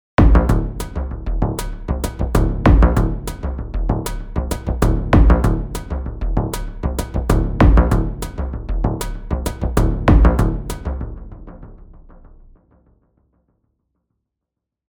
Über den Sequencer kann man aus dieser Frame Drum eine Basslinie machen:
Für den Bass füge ich ein paar Noten hinzu, bringe Dynamik ins Spiel und verwende auch verschiedene Werte für ENV SP. Der LFO 1 moduliert zudem den Filter-Cutoff: